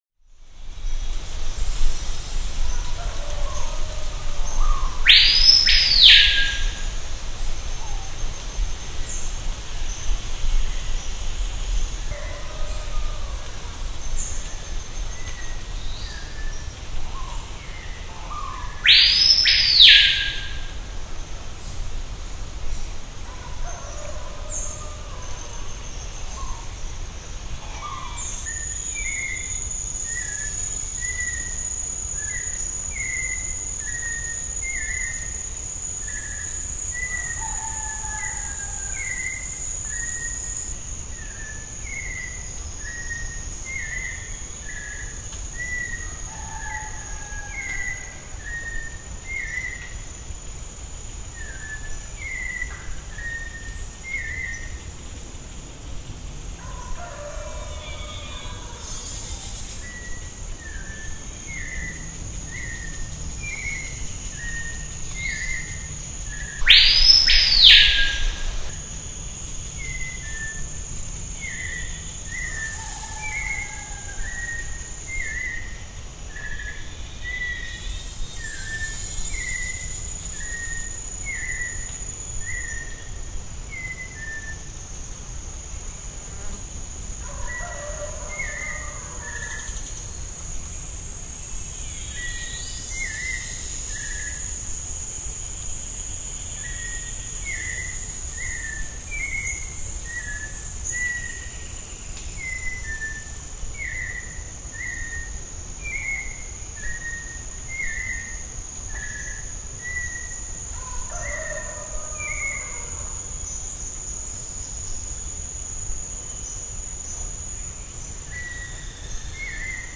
Birds, frogs and many hidden insects are the musicians.
In the Amazon jungle
In the background the rooster of a nearby Indian village.